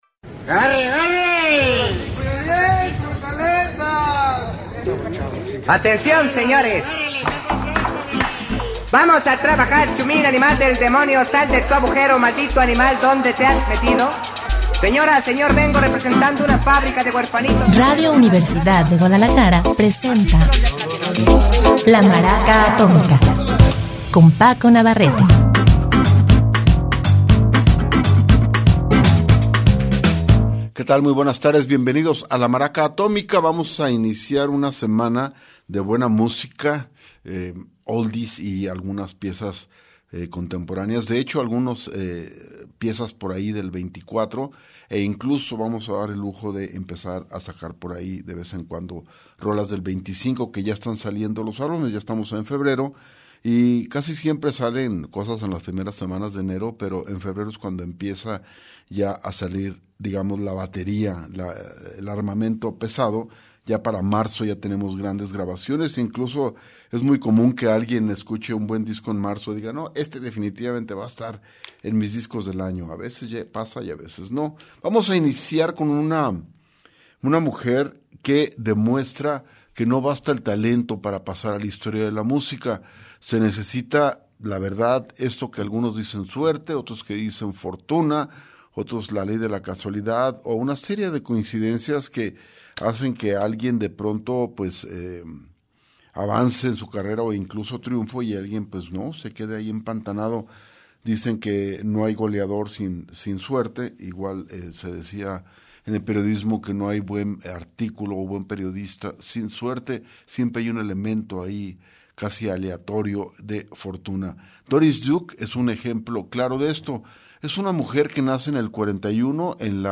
Iniciamos la semana en grande con rolas muy chingonas